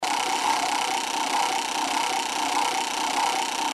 ruleta.mp3